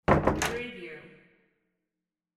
Door close sound effect .wav #18
Description: Room door closes
Properties: 48.000 kHz 24-bit Stereo
Keywords: door, close, closing, pull, pulling, push, pushing, shut, shutting, house, apartment, office, room
door-18-close-preview-1.mp3